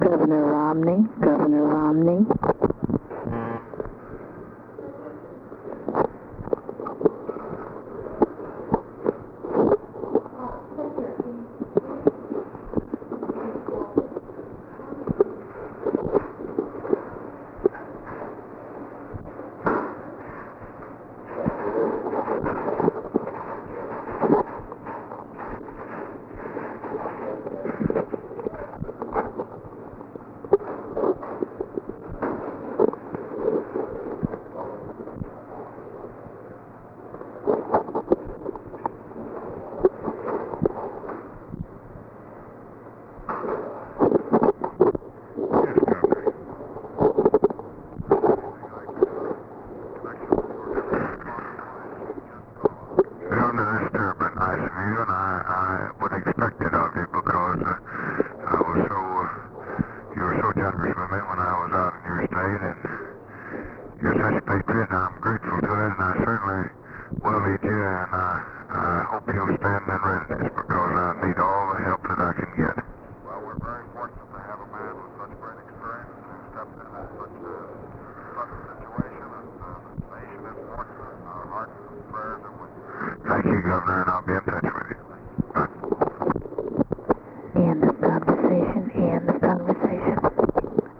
Conversation with GEORGE ROMNEY, November 23, 1963
Secret White House Tapes